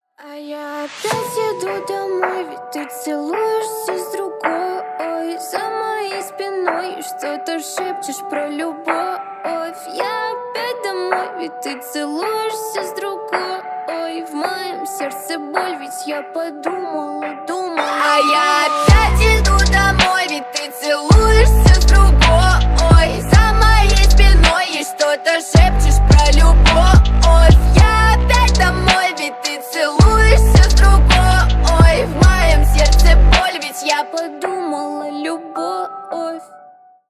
Поп Музыка
тихие # грустные